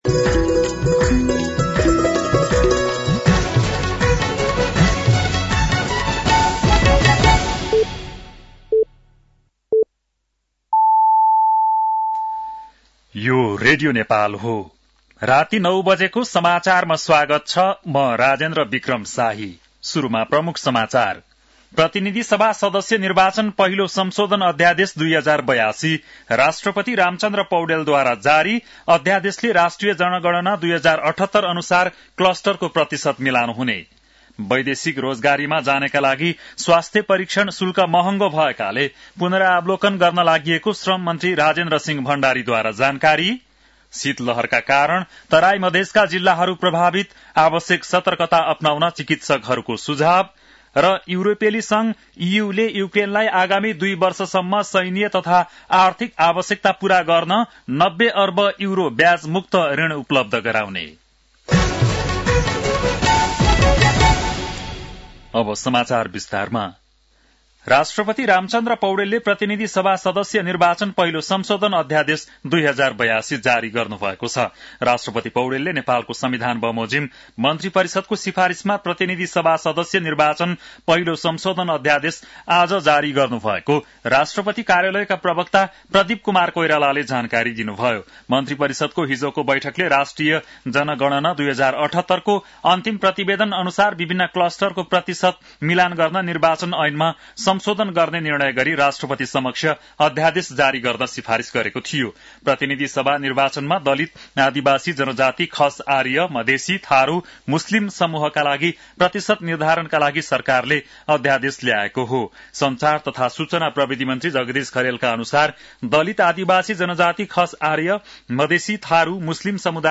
बेलुकी ९ बजेको नेपाली समाचार : ४ पुष , २०८२
9-PM-Nepali-NEWS-9-04.mp3